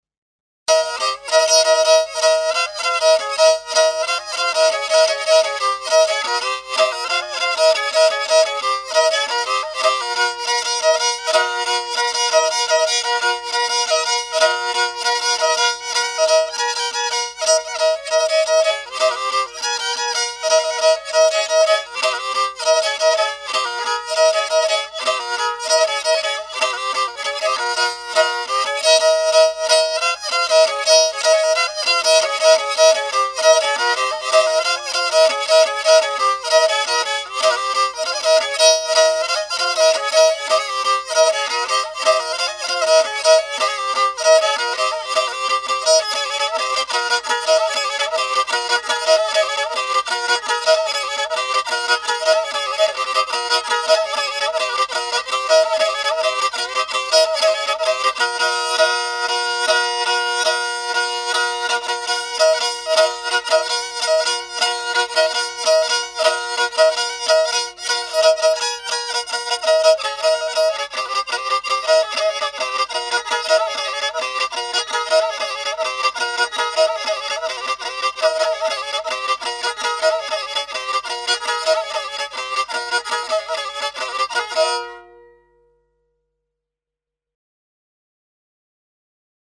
Sözlü, Sözsüz Yöresel Müzikler